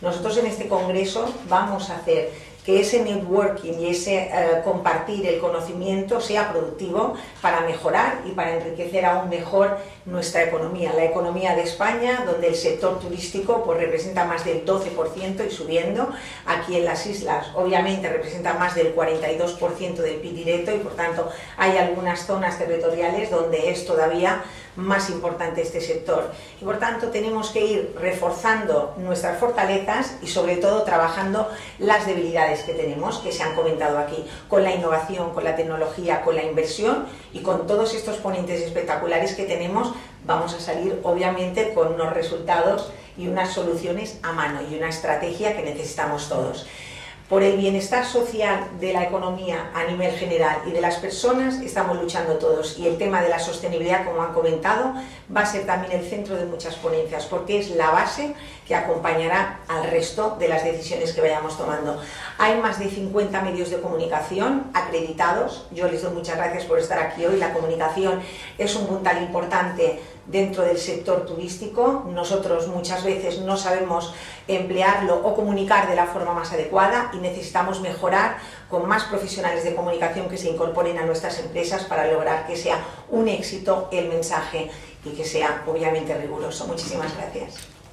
22/11: ARRANCA EL CONGRESO CON LA RECEPCIÓN EN EL AYUNTAMIENTO DE PALMA